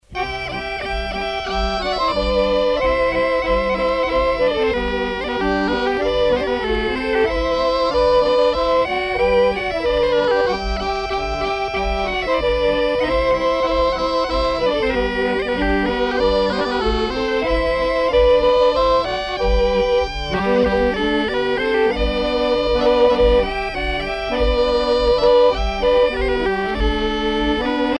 Bourrée poitevine
danse : bourree
Pièce musicale éditée